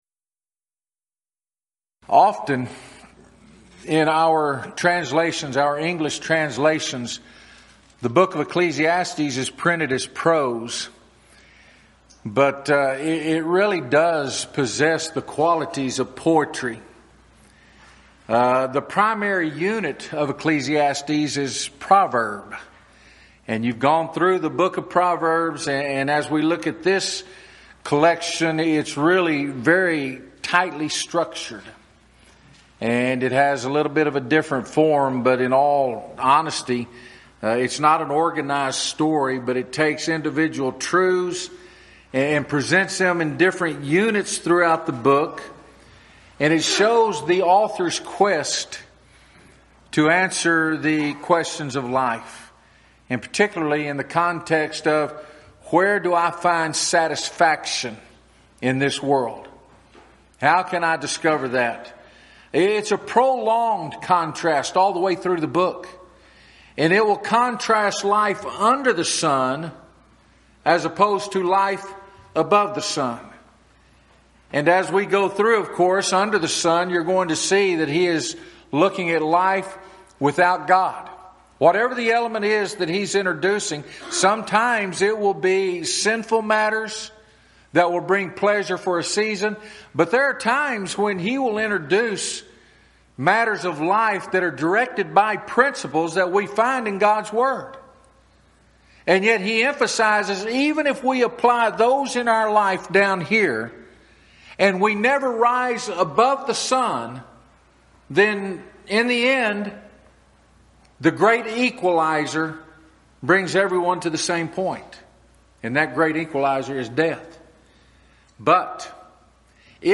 Series: Schertz Lectureship Event: 13th Annual Schertz Lectures